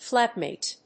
音節flát･màte